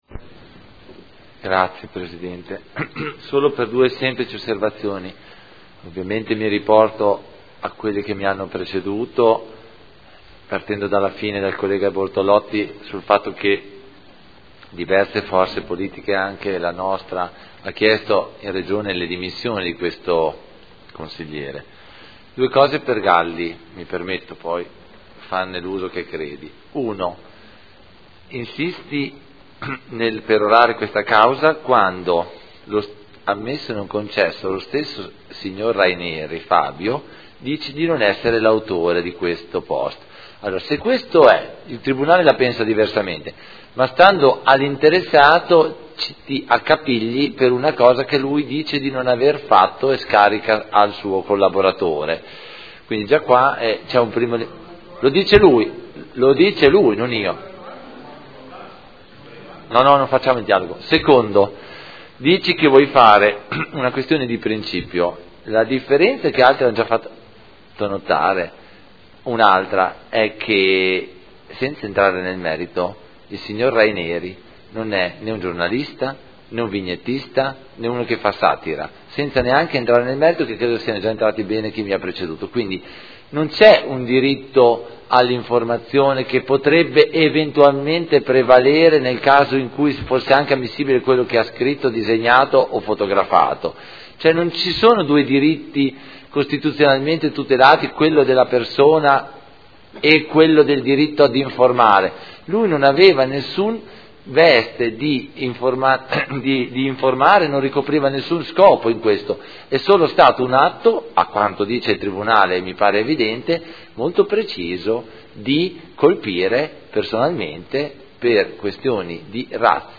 Antonio Carpentieri — Sito Audio Consiglio Comunale
Seduta del 30/03/2015.